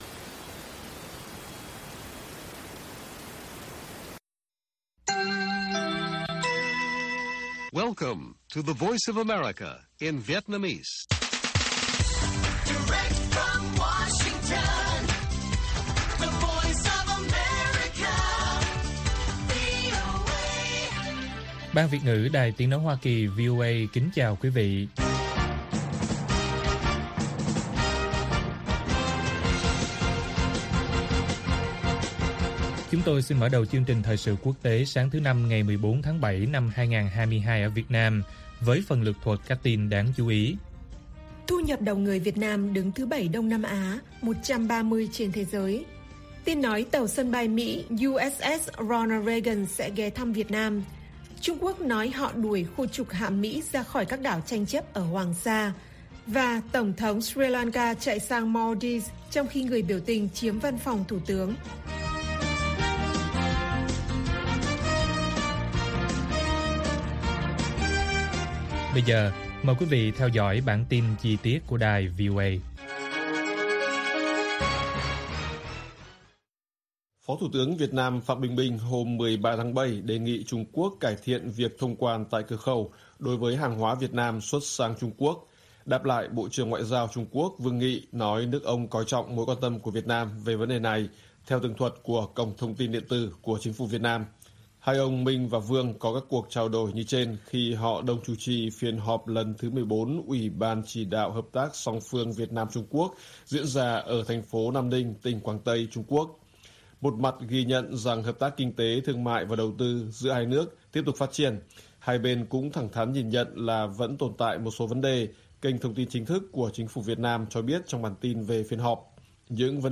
TQ nói họ 'đuổi' khu trục hạm Mỹ ra khỏi các đảo tranh chấp ở Hoàng Sa - Bản tin VOA